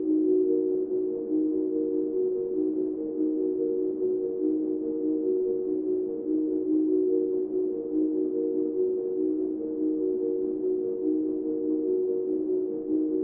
描述：陷阱和科幻的结合。沉重的打击和神秘感。
Tag: 145 bpm Trap Loops Synth Loops 2.23 MB wav Key : D